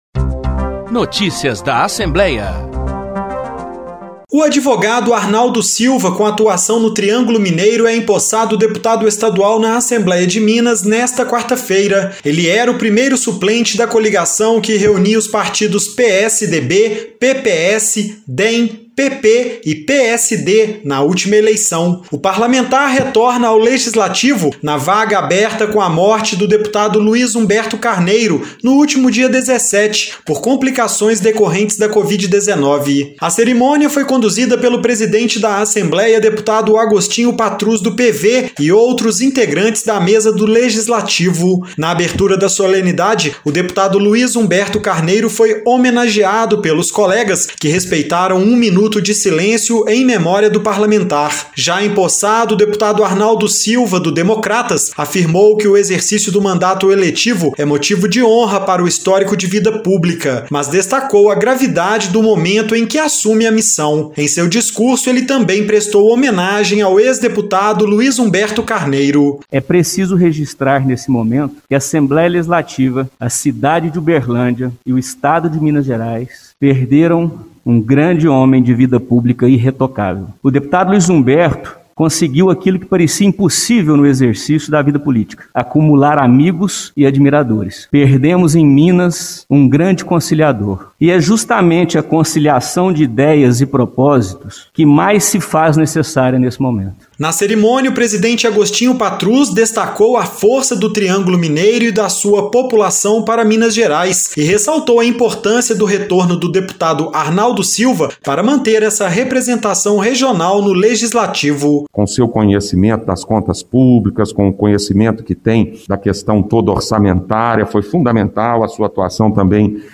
Cerimônia foi realizada, na manhã desta quarta-feira (28), no Salão Nobre da Assembleia